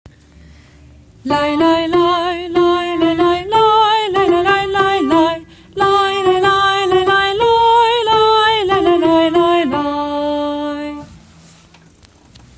请各位参加表演的老师下载女中音、男声的音频，熟悉旋律并加强歌词的记忆，保持最佳状态，按时到场。